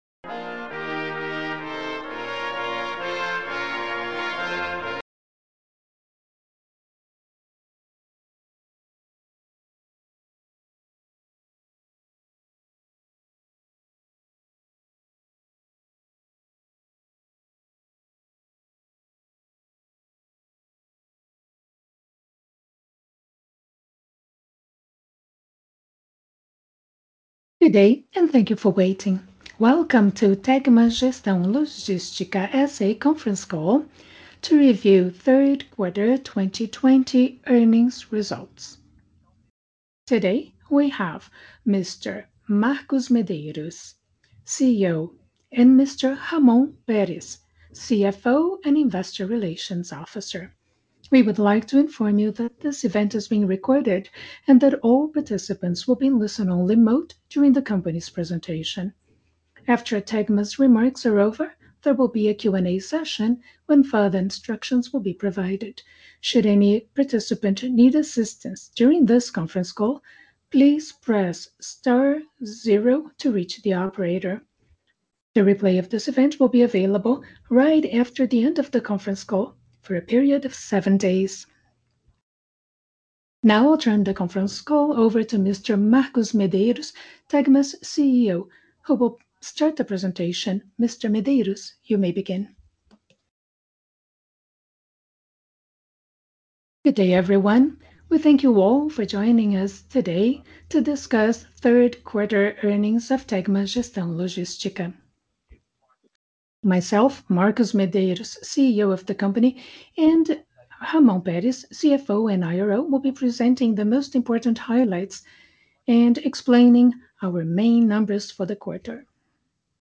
Click here for the results presentation and here for the results conference replay.